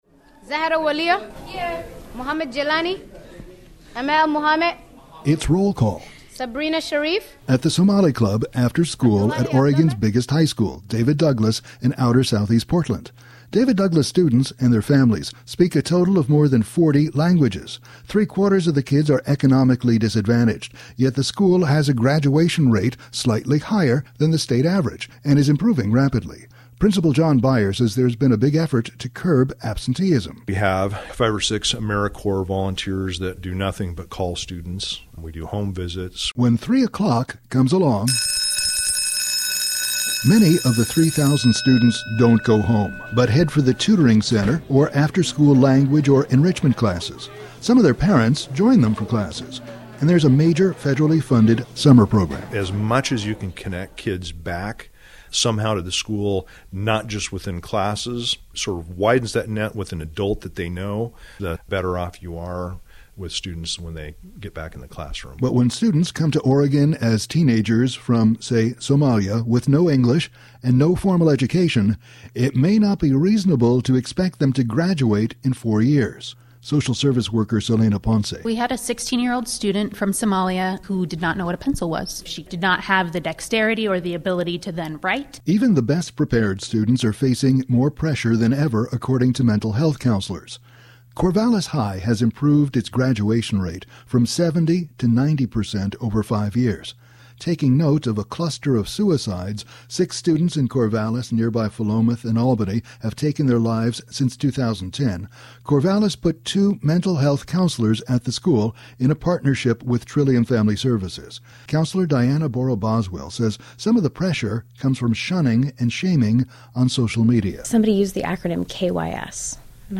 It's roll call at the Somali club after school at Oregon's biggest high school, David Douglas in outer southeast Portland.